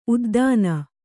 ♪ uddāna